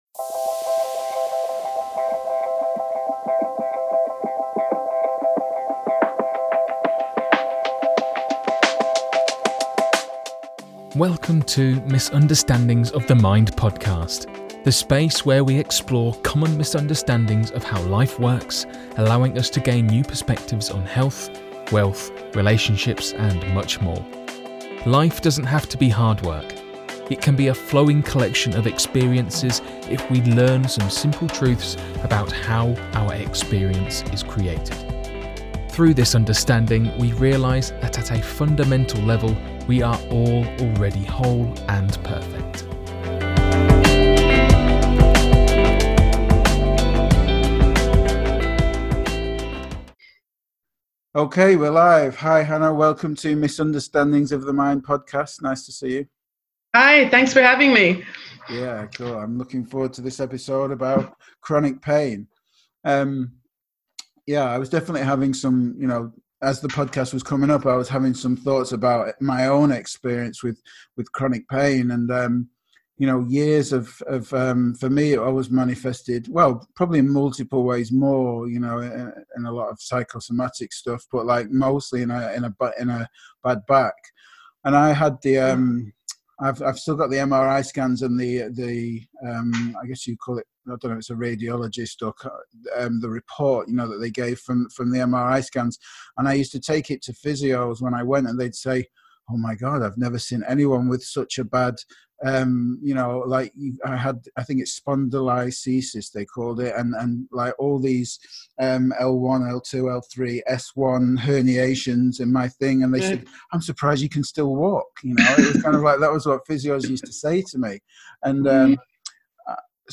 A life changing conversation for anyone who struggles with chronic pain, migraines, skin conditions that are affected by stress.